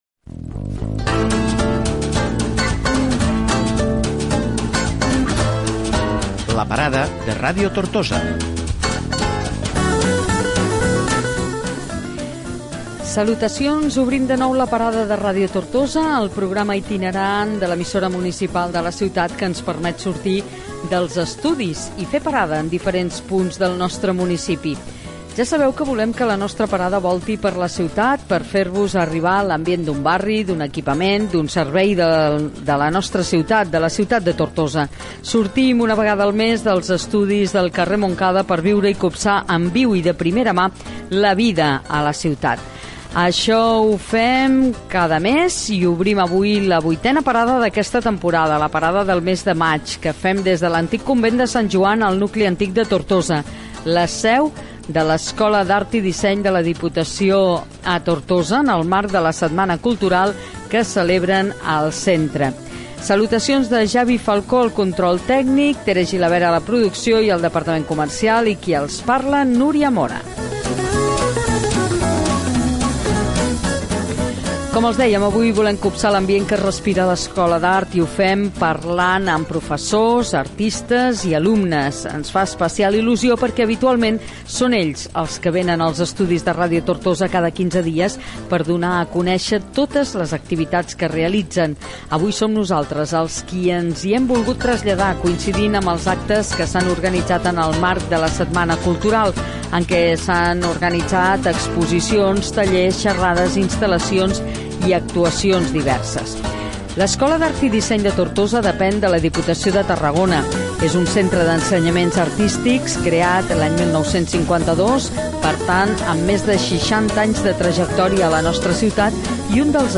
Indicatiu del programa, presentació del programa que es fa des del Convent de Sant Joan de Tortosa, seu de l'Escola d'Art i Disseny de Tortosa, per parlar de la setmana cultural que s'hi ha celebrat.
Entreteniment